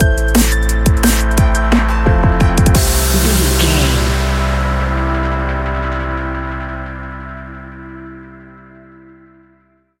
Ionian/Major
Fast
driving
uplifting
lively
futuristic
hypnotic
industrial
drum machine
synthesiser
electric piano
electronic
sub bass